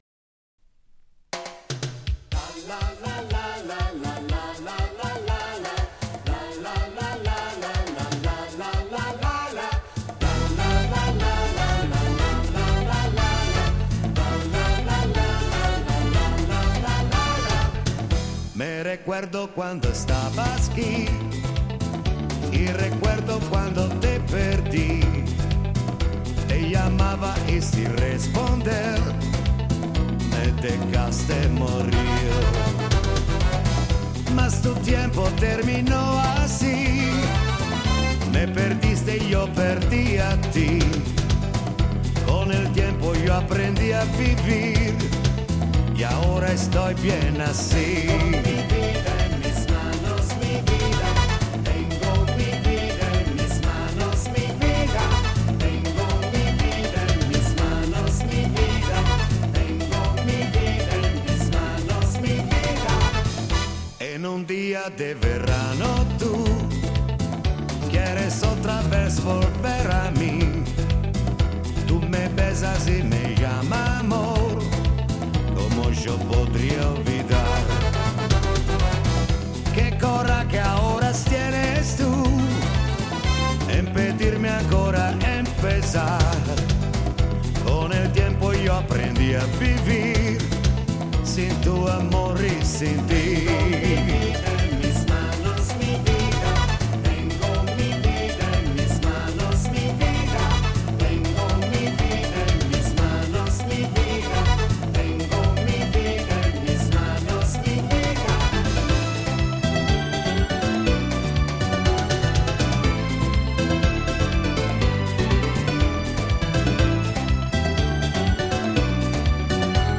Genere: Lambada